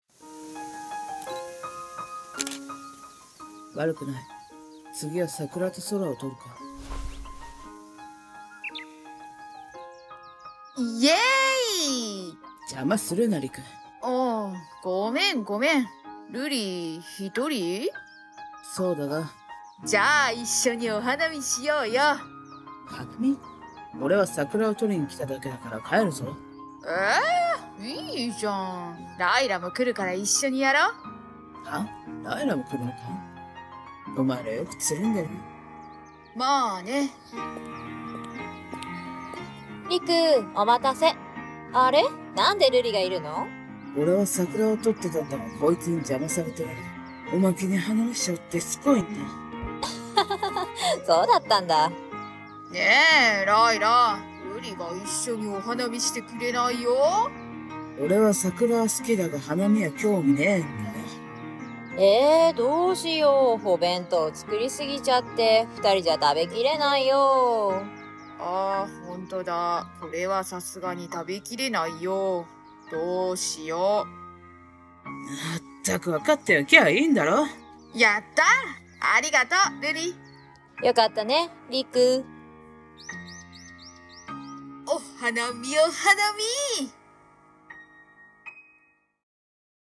声劇 春 前編